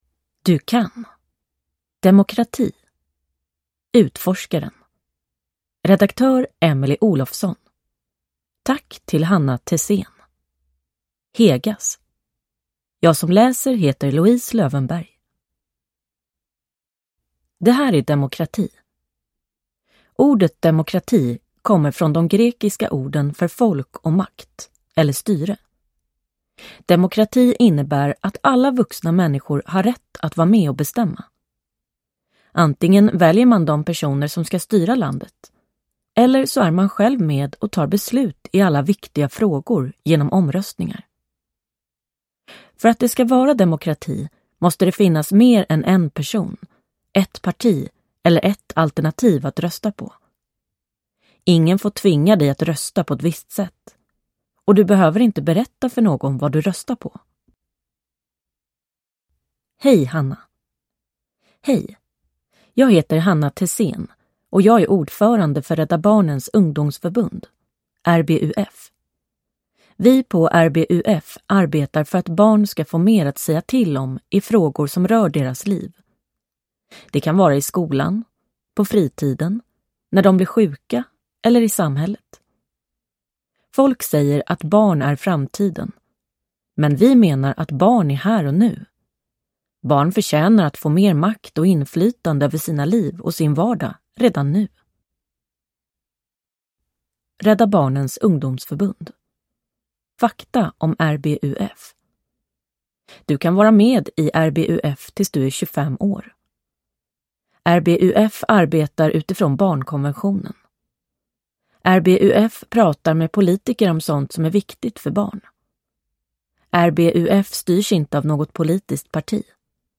Demokrati (ljudbok) av -